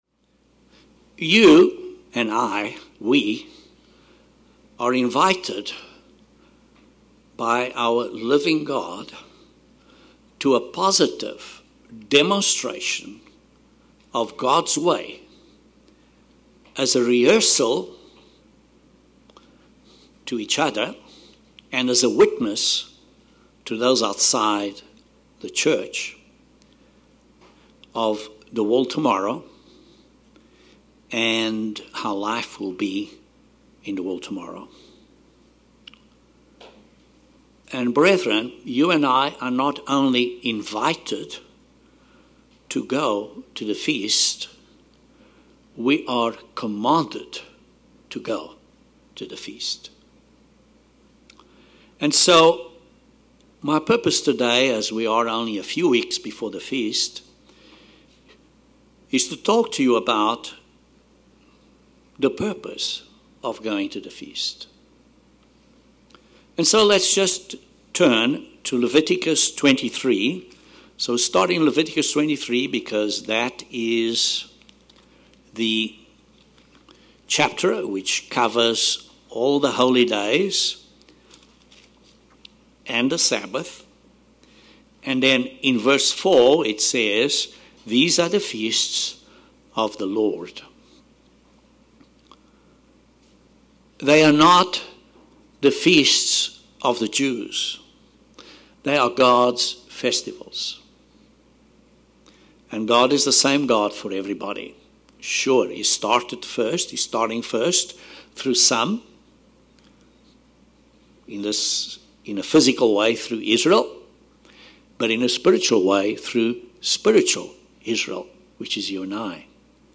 You are not only invited to go, but you are also commanded to go to learn to fear God. The sermon describes how we learn to respect God by (i) understanding His plan for humanity; by (ii) applying God’s commandment to love one another, through godly fellowship; and (iii) by rejoicing through service.